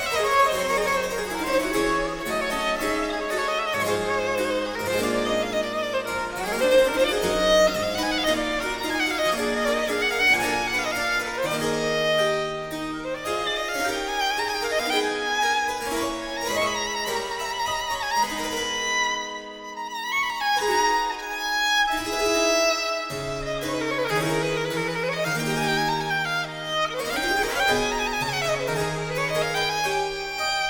2 -- Sonata, la mineur